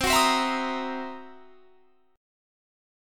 C7#9b5 chord